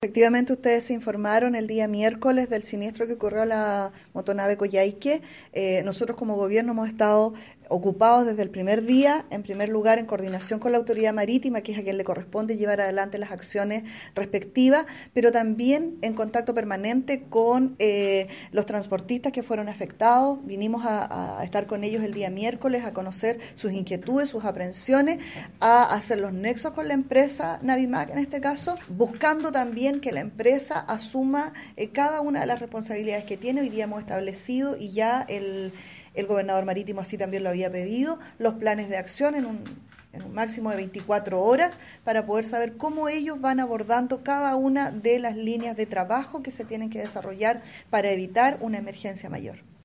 Tras la reunión de coordinación del Comité Técnico que se llevó a cabo, la intendenta de la región de Aysén, Yeoconda Navarrete, abordó las labores que se están realizando con la Armada, más la colaboración de diversas seremías y ONEMI, ante el varamiento de la Motonave “Coyhaique”.